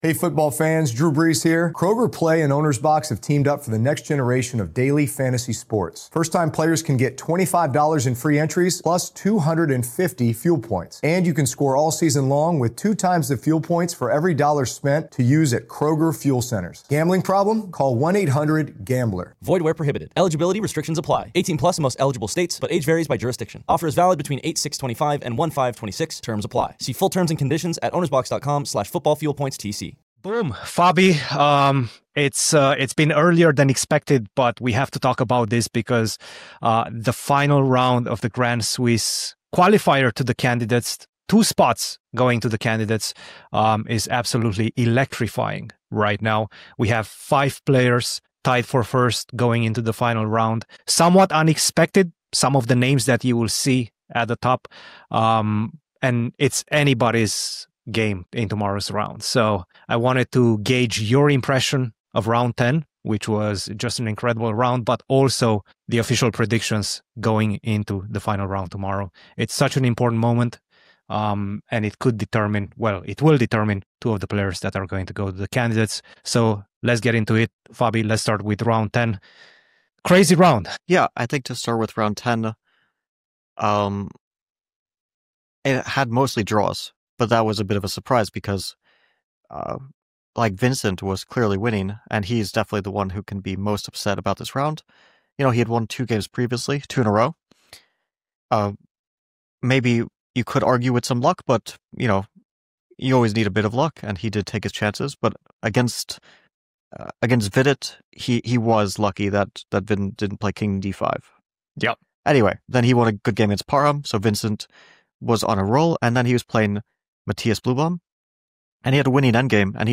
The C-Squared Podcast is an in depth weekly discussion about the chess world with your hosts